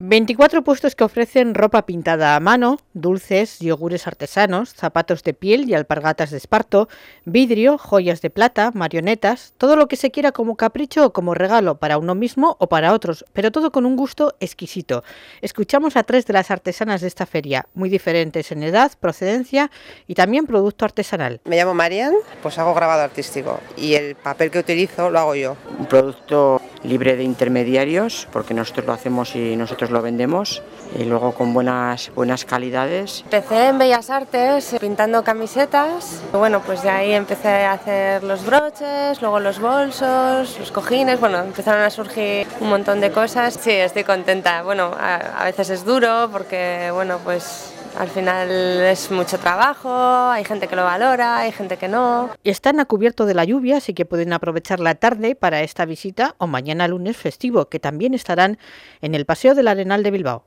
Entrevistas en los medios